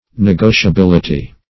Search Result for " negotiability" : The Collaborative International Dictionary of English v.0.48: Negotiability \Ne*go`ti*a*bil"i*ty\ (? or ?), n. [Cf. F. n['e]gociabilit['e].]
negotiability.mp3